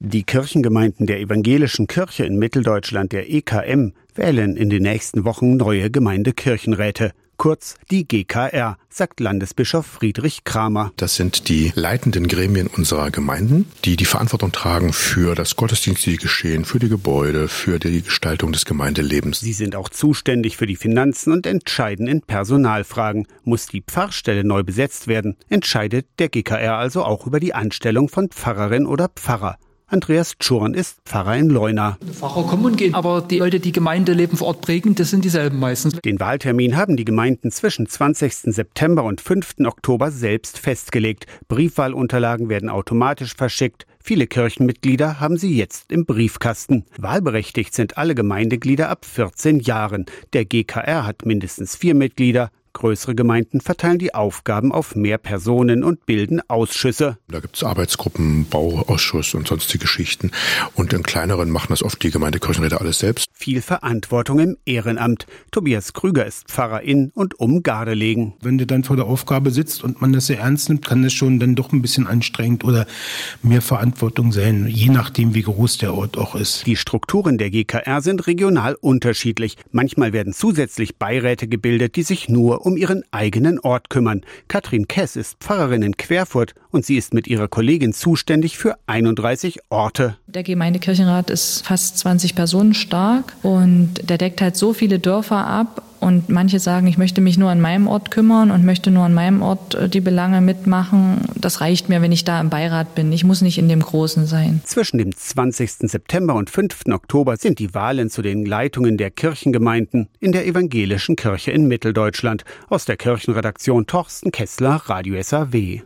Interviewte